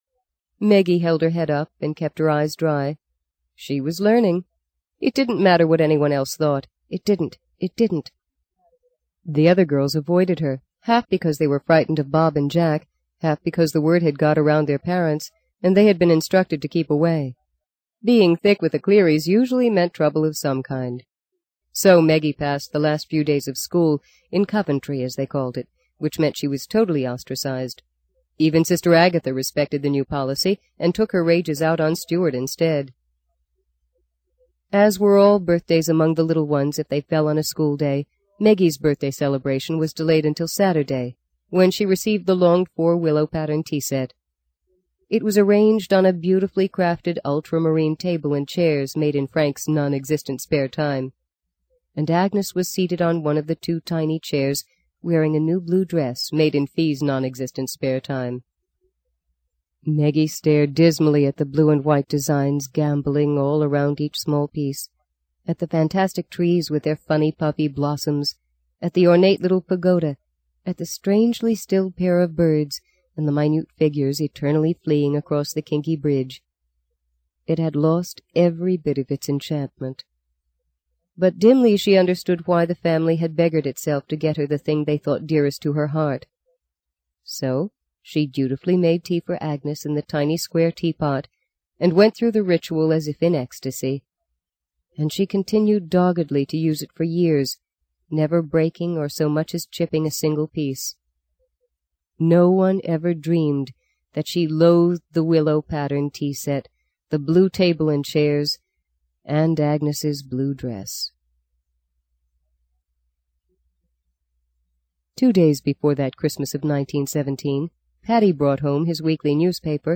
在线英语听力室【荆棘鸟】第二章 23的听力文件下载,荆棘鸟—双语有声读物—听力教程—英语听力—在线英语听力室